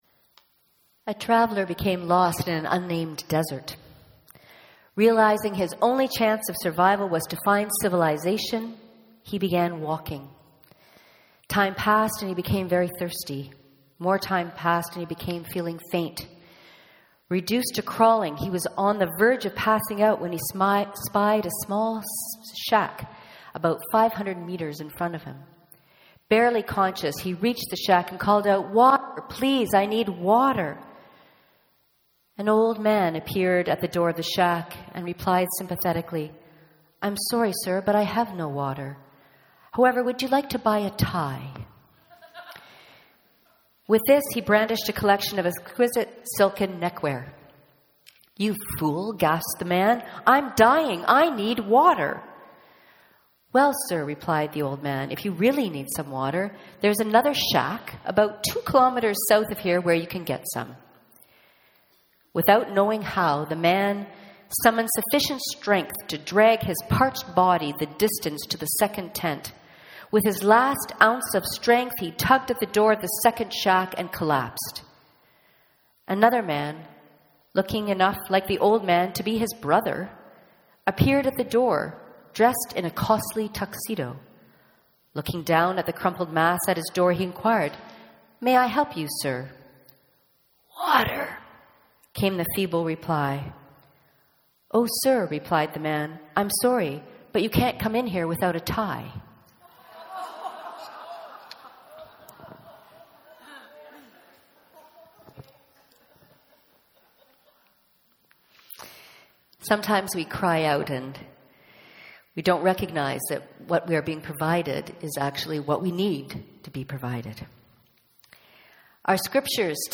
Sermons | Knox United Church